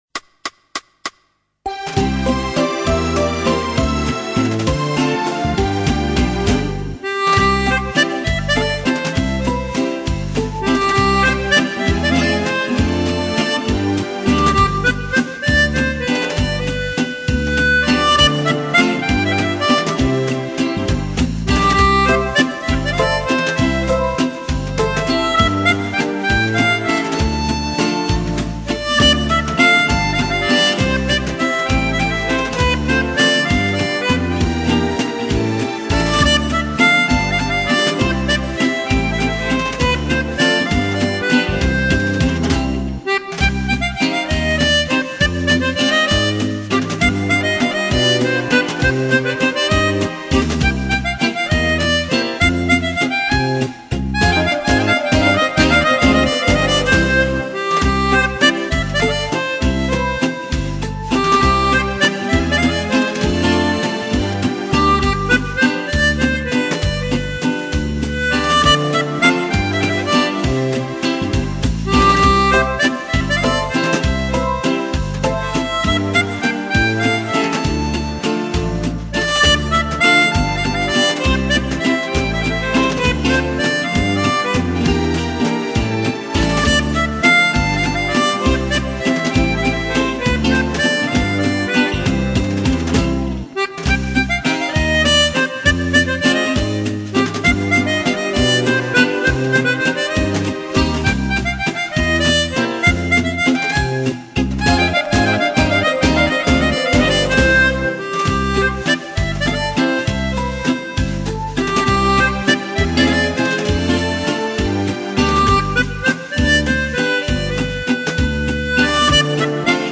Merci pour cette valse musette ...